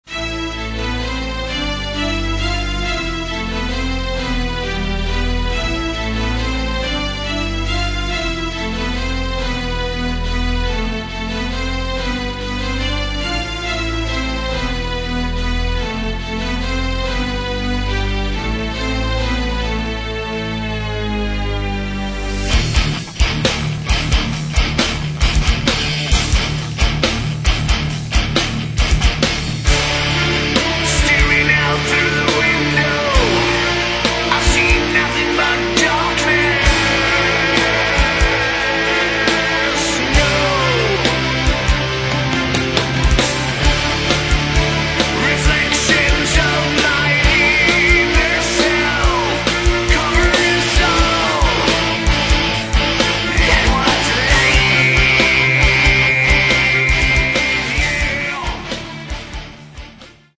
Metal
вокал, гитары
бас
клавишные
ударные